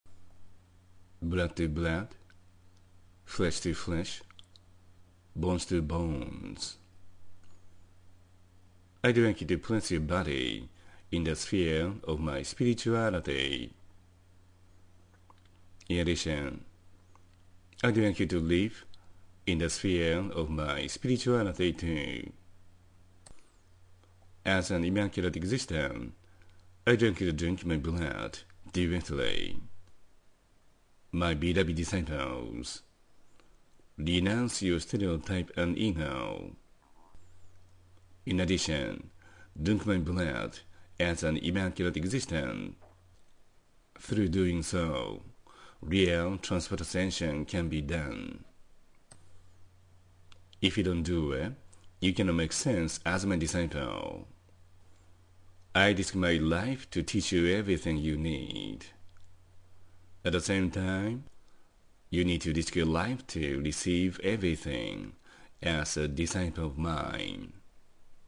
⇒　Blood to blood, flesh to flesh and bones to bones Ⅰ（英語音声講義）